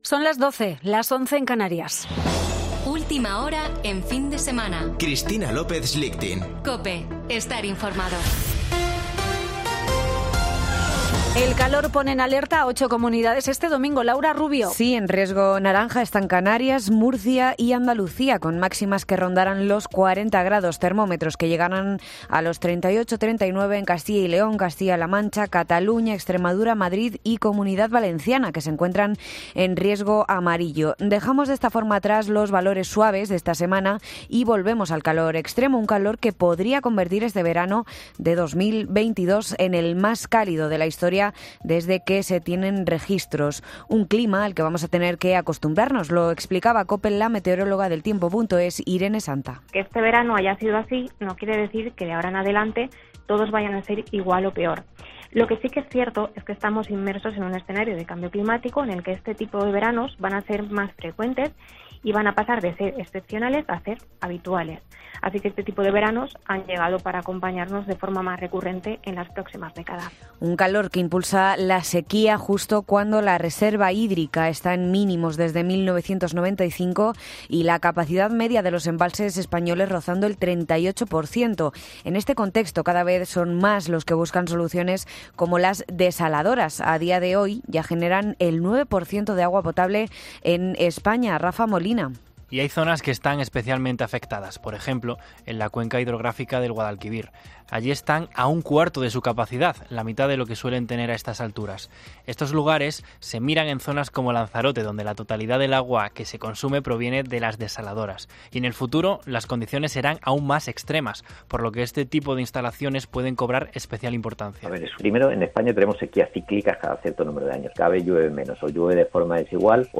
Boletín de noticias de COPE del 21 de agosto de 2022 a las 12.00 horas